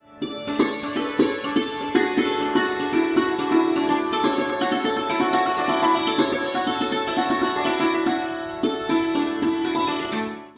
santur